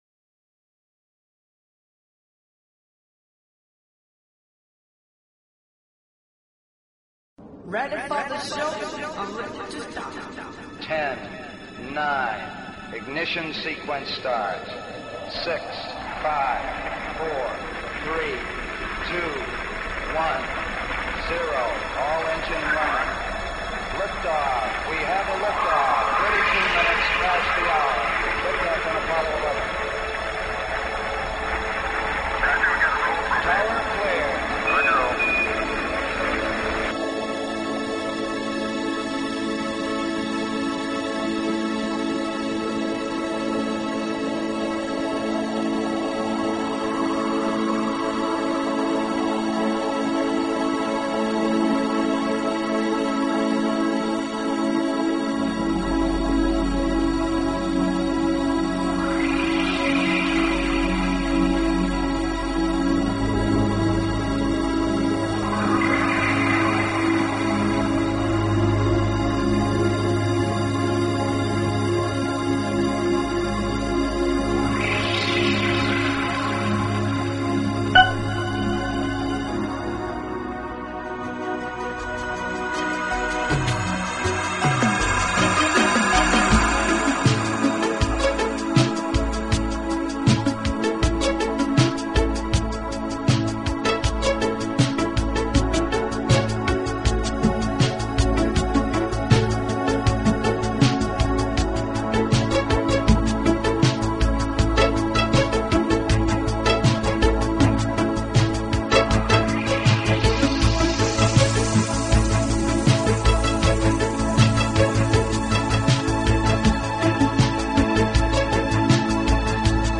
Talk Show Episode, Audio Podcast, ET-First_Contact_Radio and Courtesy of BBS Radio on , show guests , about , categorized as